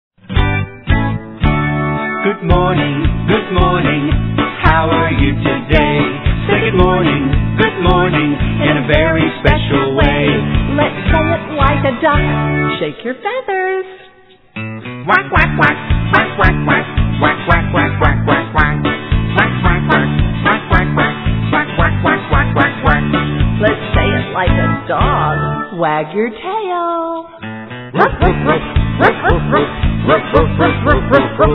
Children's Song Lyrics and Sound Clip